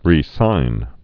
(rē-sīn)